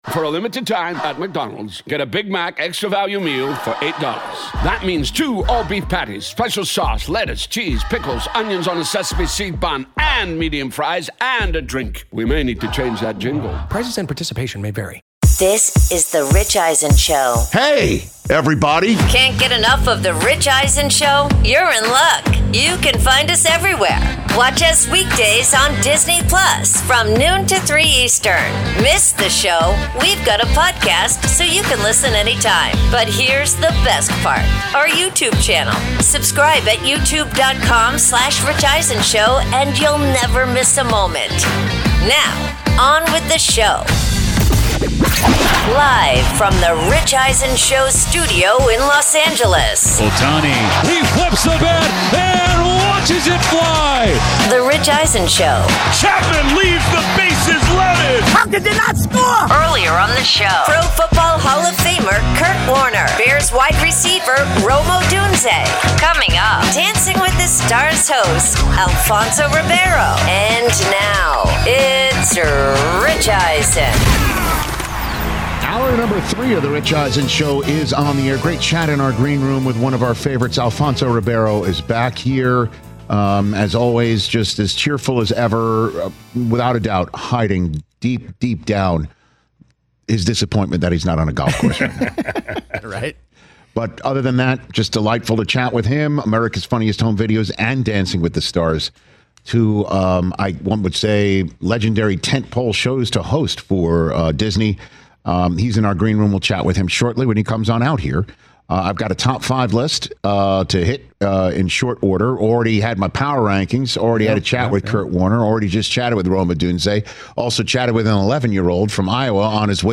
Hour 3: Top 5 Most Disappointing NFL Teams, plus Alfonso Ribeiro In-Studio